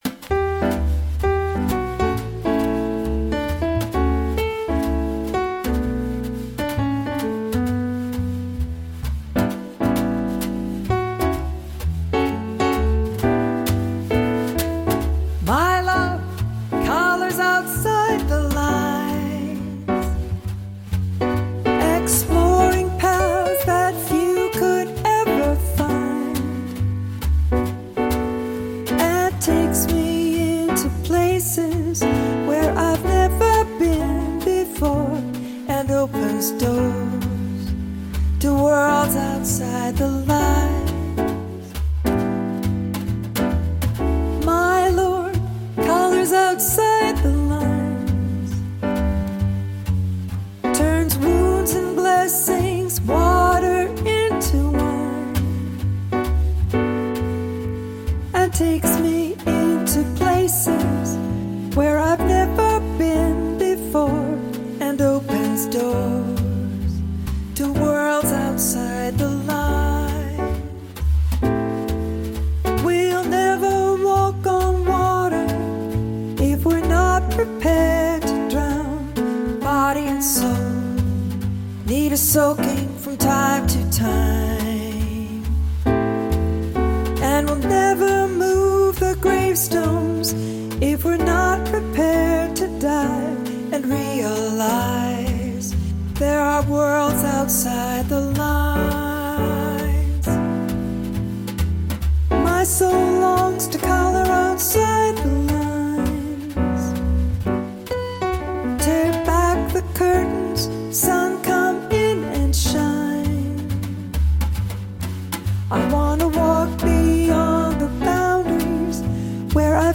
HYMN: Gordon Light ©